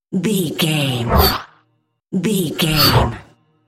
Sci fi whoosh fast
Sound Effects
futuristic
intense
whoosh